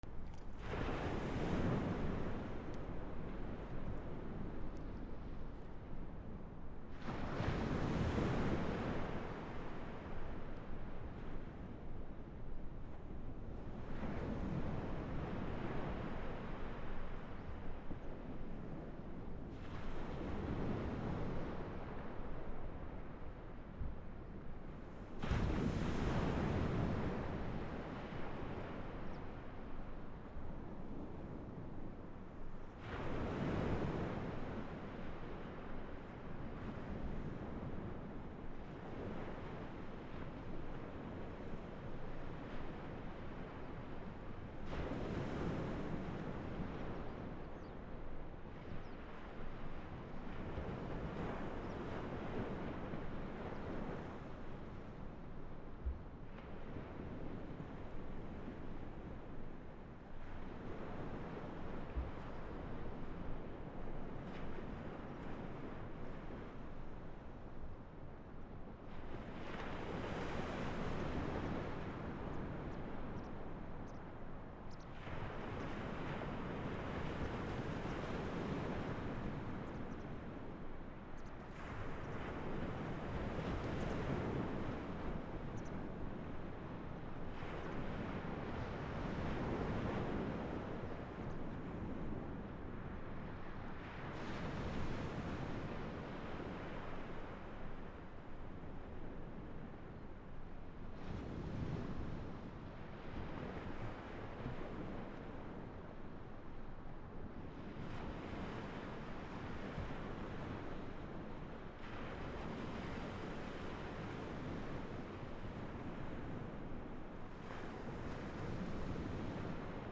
/ B｜環境音(自然) / B-10 ｜波の音 / 波の音
波の音 直江津 波打ち際から約20m